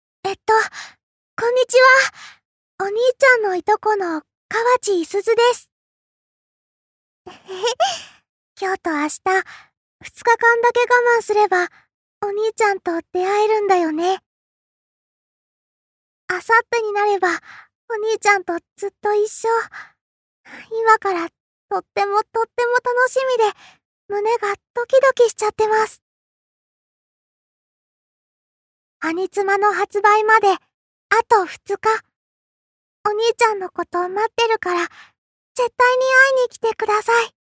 カウントダウンボイス公開！